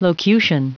Prononciation du mot locution en anglais (fichier audio)